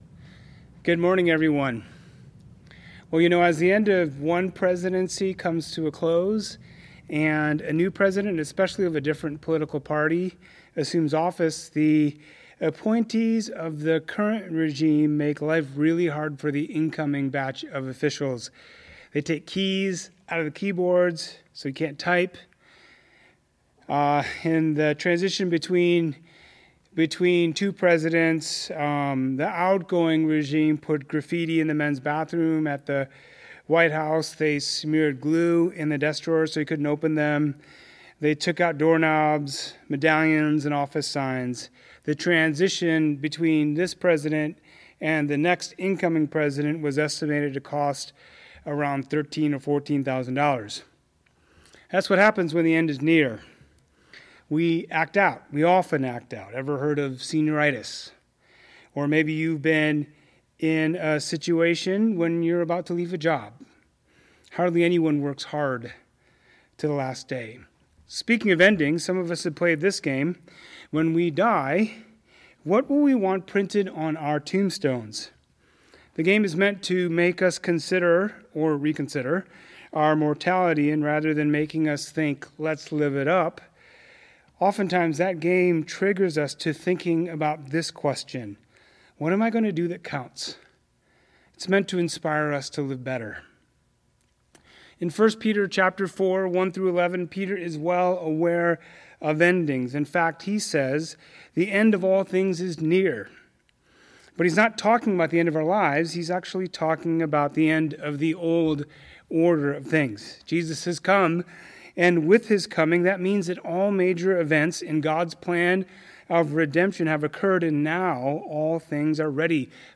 Peter tells us in 4:1-11 to live rightly ordered lives, with the will of God at the center.”Preacher